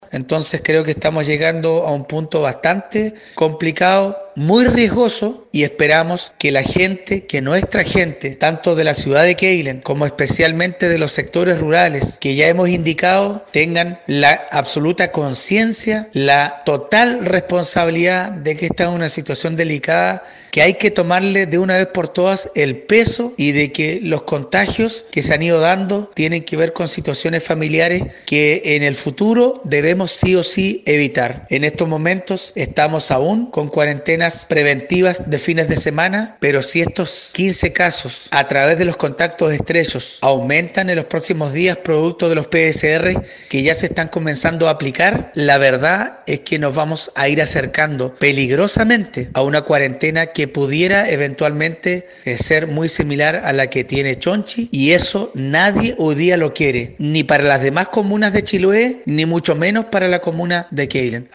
La autoridad de Quéilen llamó de manera muy tajante a la población para reconocer de una vez por todas que se trata de una situación muy delicada, más aun tomando en consideración la cantidad de habitantes de Quéilen, donde podría decretarse cuarentena total.